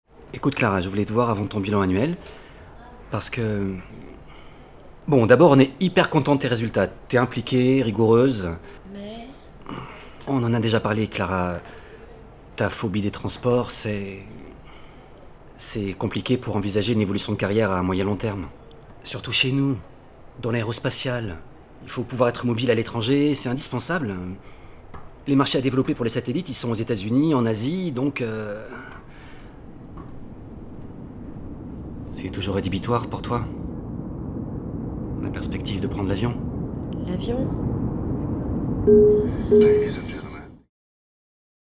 Voix off TELETHON / PUB FRANCE 2
30 - 45 ans - Baryton